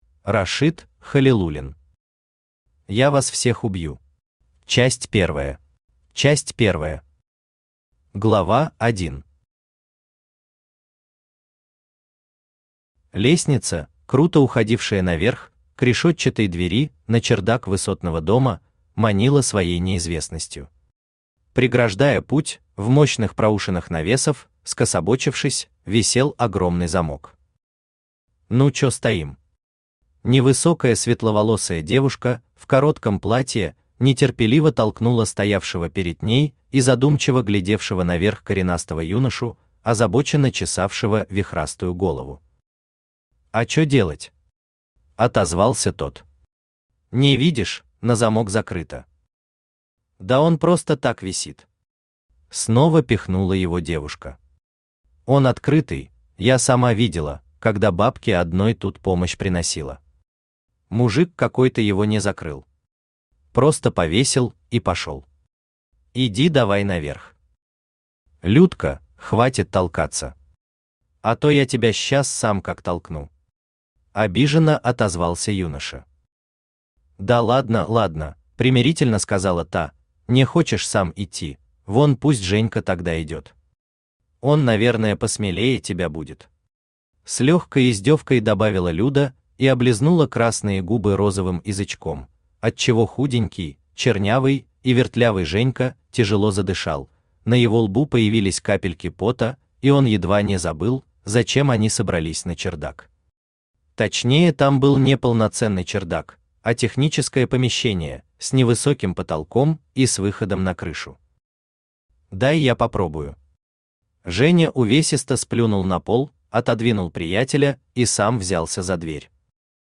Аудиокнига Я вас всех убью. Часть первая | Библиотека аудиокниг
Часть первая Автор Рашит Халилуллин Читает аудиокнигу Авточтец ЛитРес.